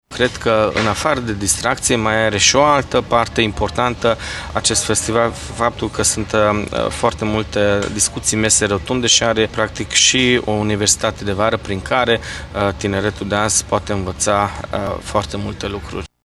Festivalul Vibe contribuie la dezvoltarea turismului din zonă, dar și a afacerilor locale, a spus Kovács Mihály Levente, viceprimarul municipiului Tg.Mureș. El a subliniat că este important ca tinerii să participe la evenimente la care să se poată distra: